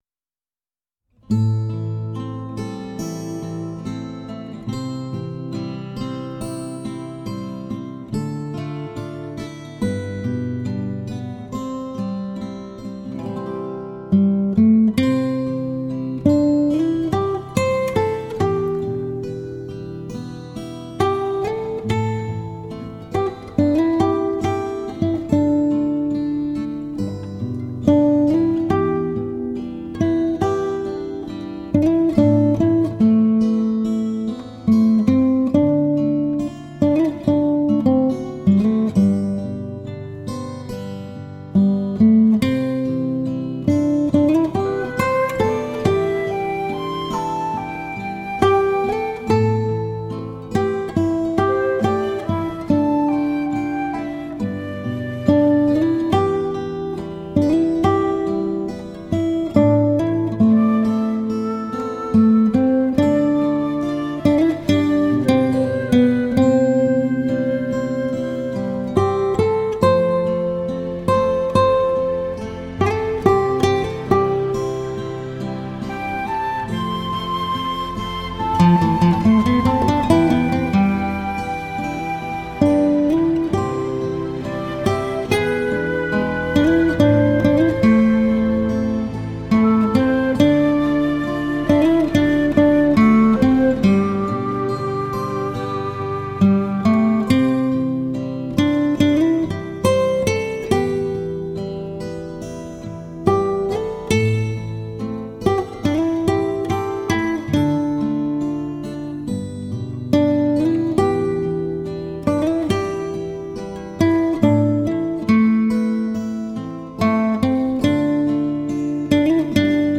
无可挑剔的技术 出神入化的演奏 空气感 解析力 各频段的还原度更是一流
主奏吉他定位稳固 伴奏弦乐温暖厚实
人声吟唱低回婉转 清越悠长
或激越 或欢快 或沉静 或感伤
HiFi吉他典范